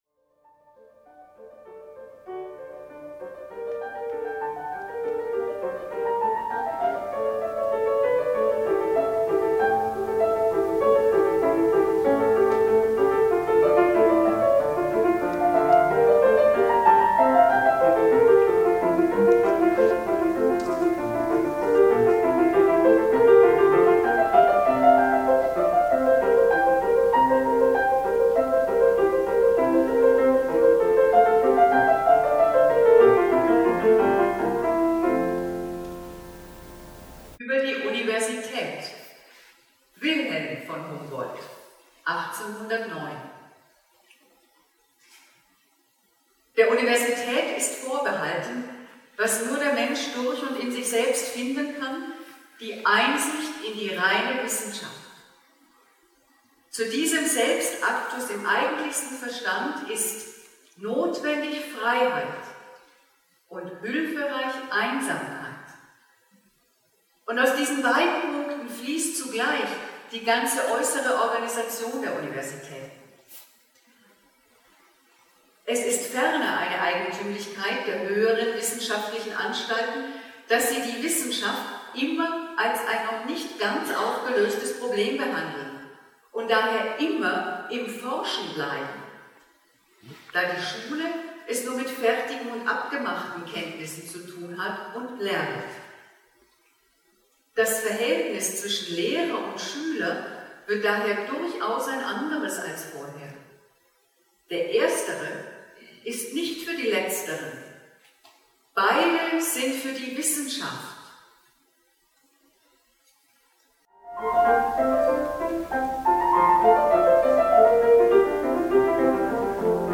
Konzertlesung in Hannover mit Text von W. von Humboldt: „Über die Universität“…